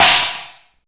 Drums05C.wav